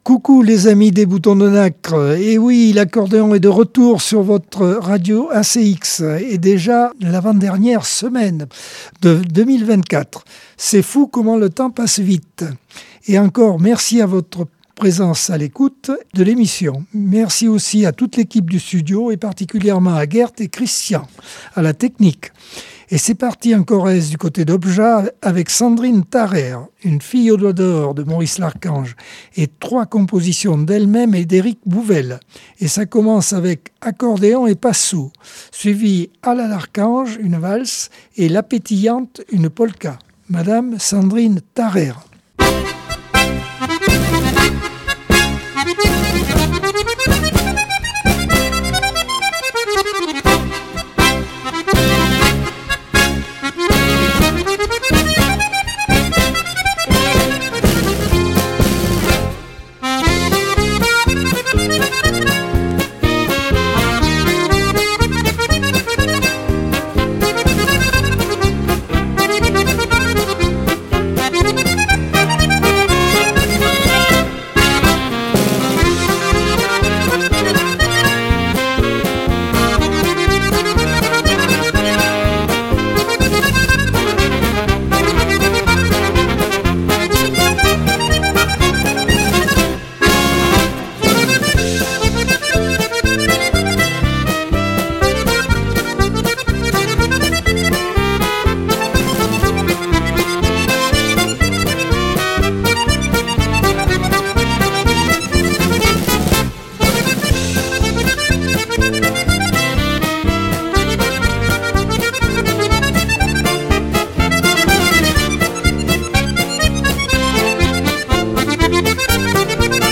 Accordeon 2024 sem 51 bloc 1 - Radio ACX